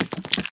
step2.ogg